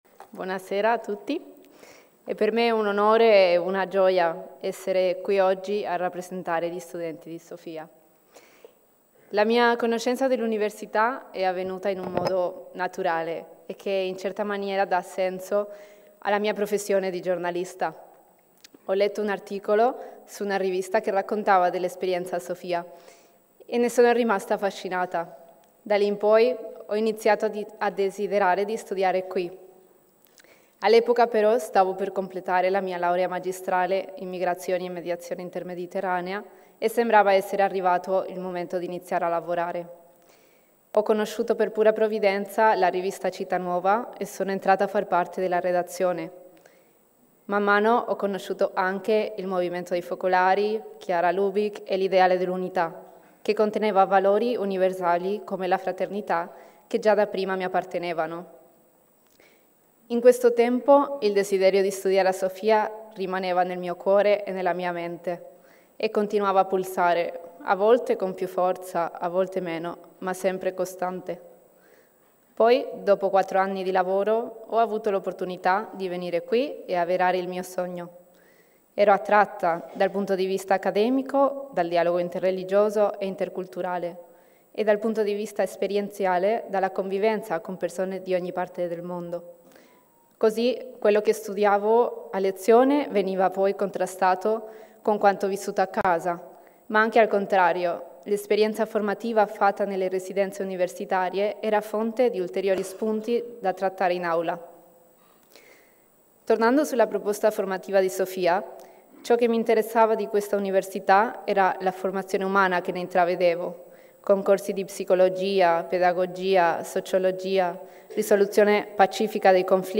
Questo il discorso pronunciato in sala: